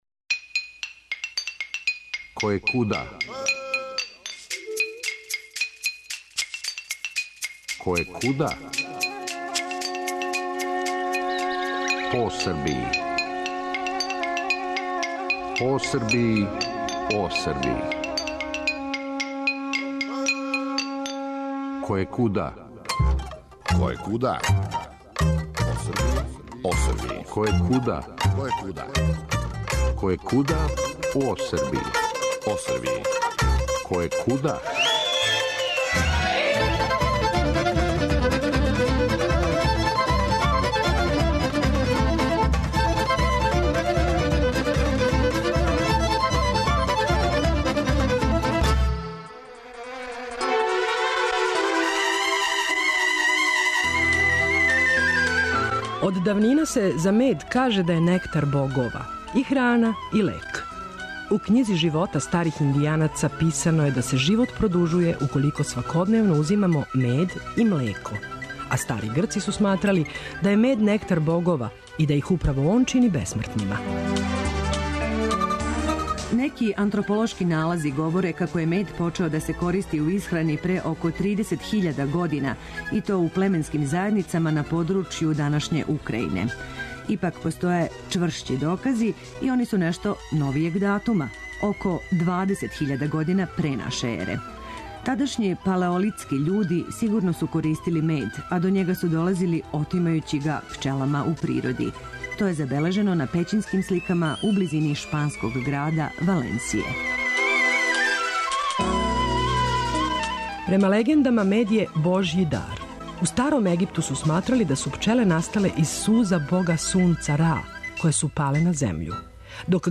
Слушаћете причу о меду са Хомоља и животу са пчелама. Смирен и благ, а такав се једино и може бити кад живите уз пчеле и кошнице, једног преподнева почетком августа, у сред лета, причао нам је своју причу.